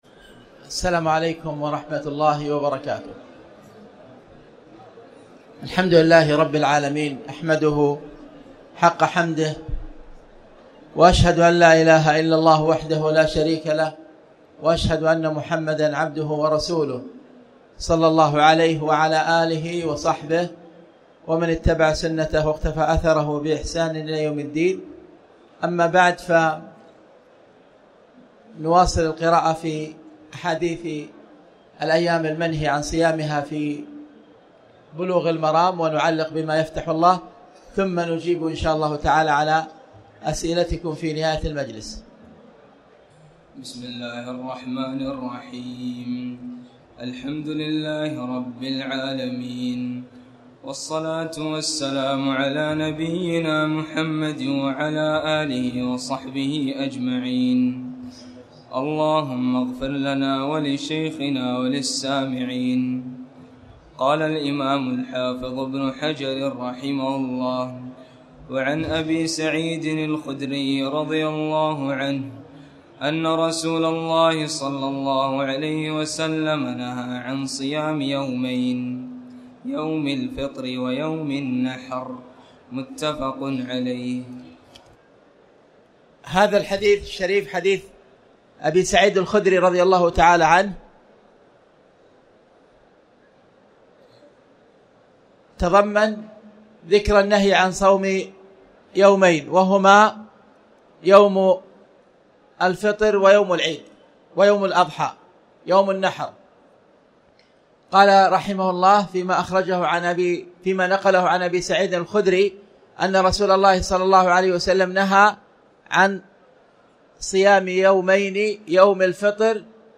تاريخ النشر ٢٥ رمضان ١٤٣٩ هـ المكان: المسجد الحرام الشيخ